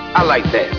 To hear Mr. T answer, just click the responses.